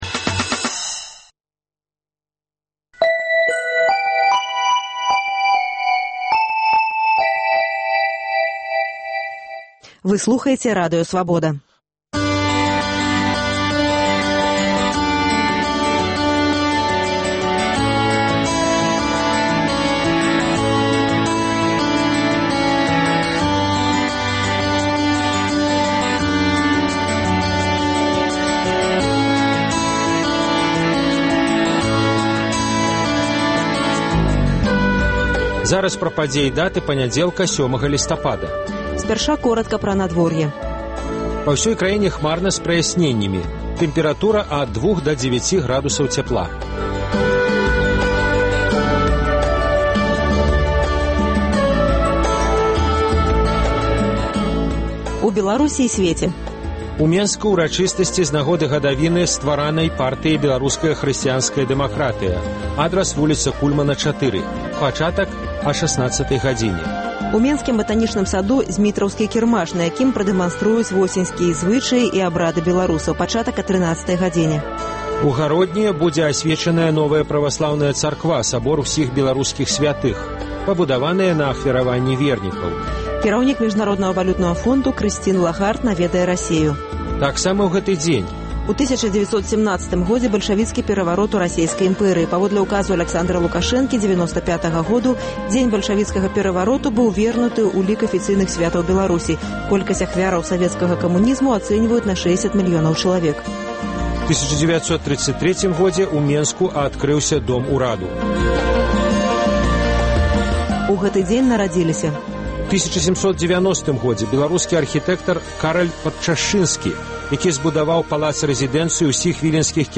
Ранішні жывы эфір
Навіны Беларусі й сьвету, надвор'е, агляд друку, гутарка з госьцем, ранішнія рэпартажы, бліц-аналіз, музычная старонка